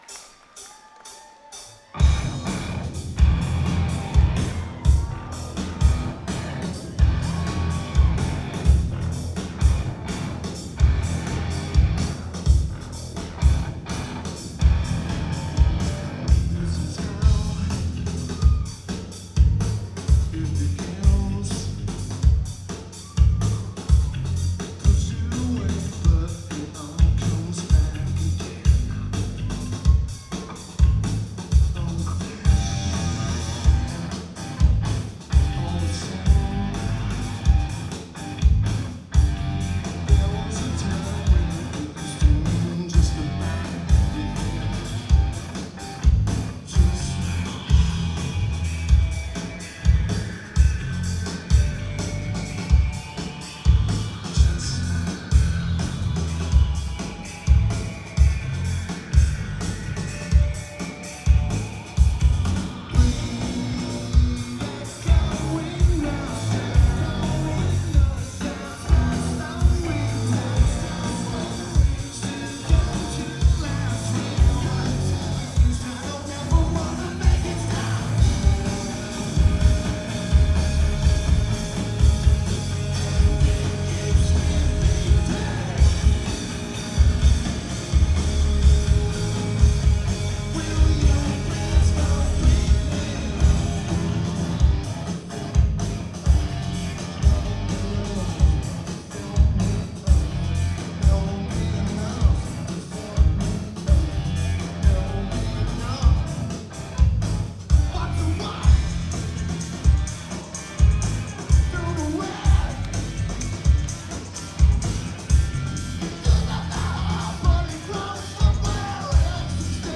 Key Club at Morongo Casino (Spiral Only Show)
Great recording!